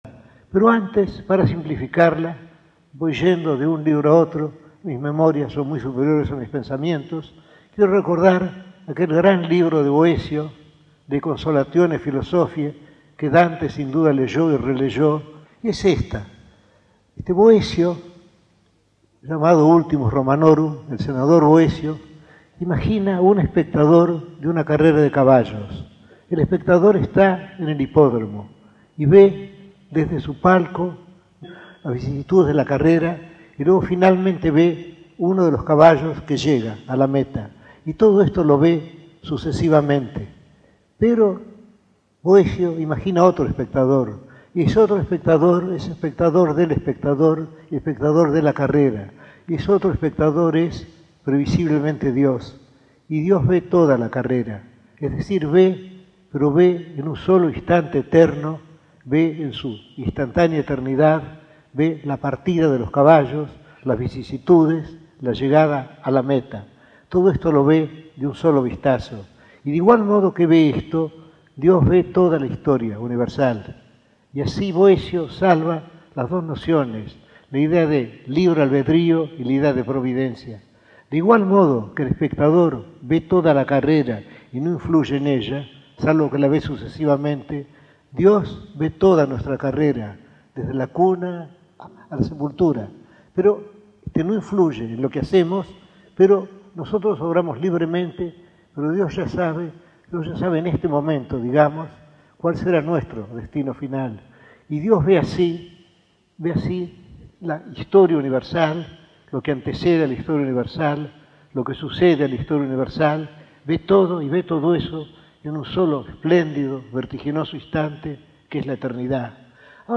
Conferencia dictada en el teatro Coliseo de BBAA, el 15 de junio de 1977